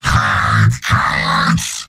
Giant Robot lines from MvM. This is an audio clip from the game Team Fortress 2 .
Heavy_mvm_m_battlecry04.mp3